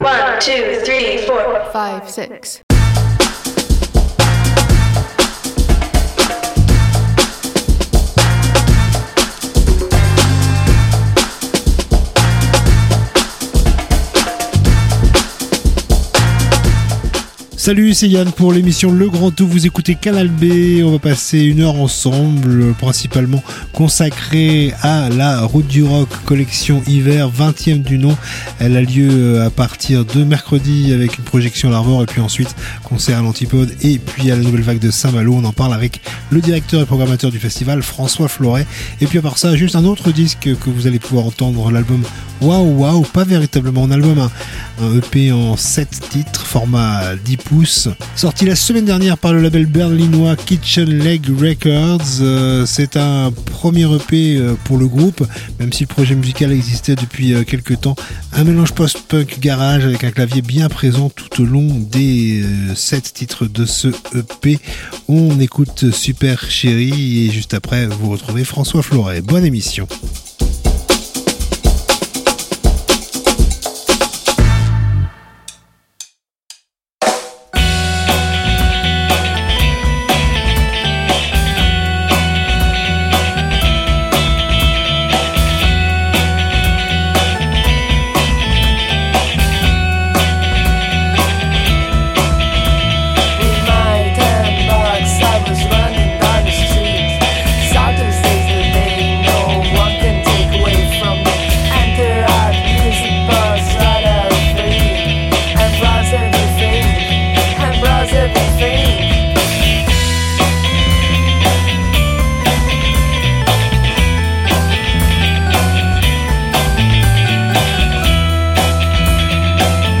itv concerts